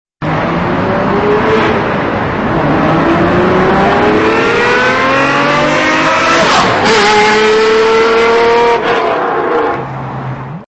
0-100 Acceleration